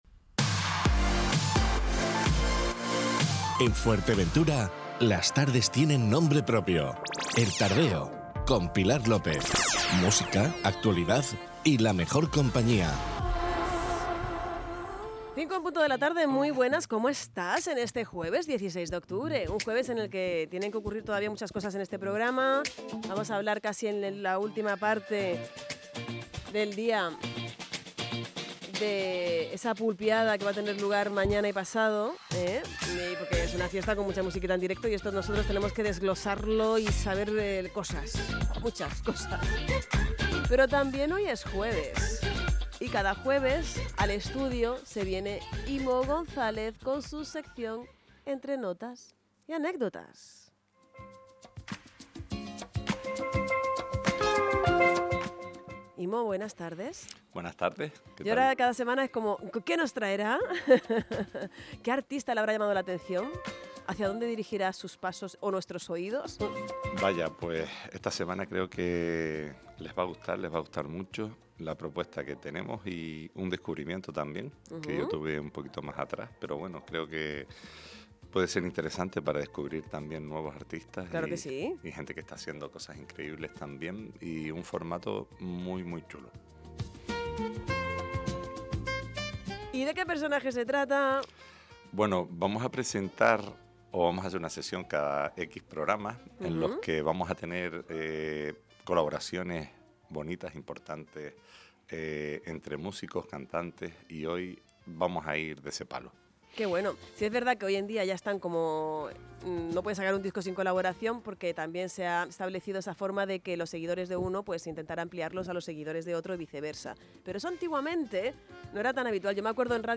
que cada jueves se emite en directo en el programa El Tardeo de Radio Insular Fuerteventura.